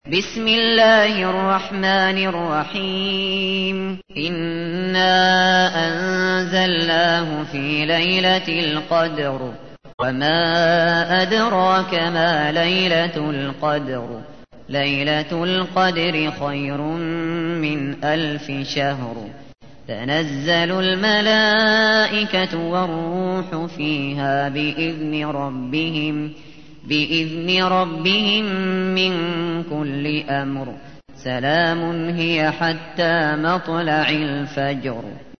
تحميل : 97. سورة القدر / القارئ الشاطري / القرآن الكريم / موقع يا حسين